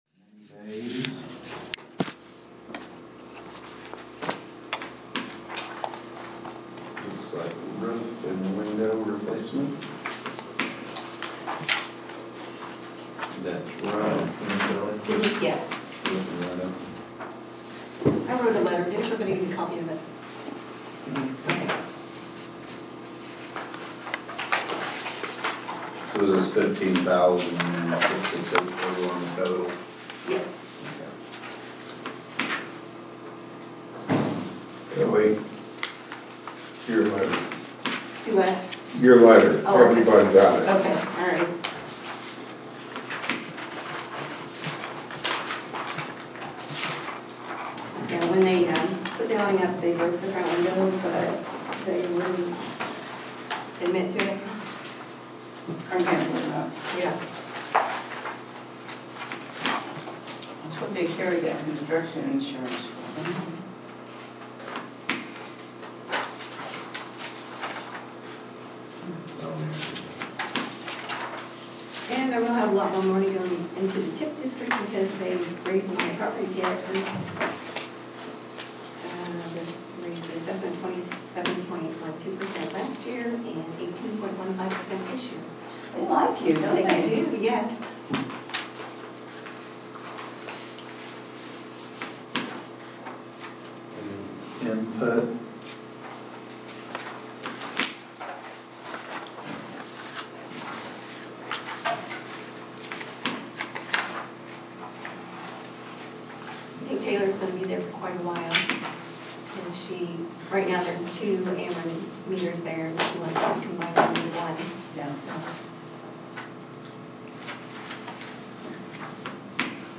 November 12th, 2024 City Council Meeting Audio
Audio recordings of City Council Meetings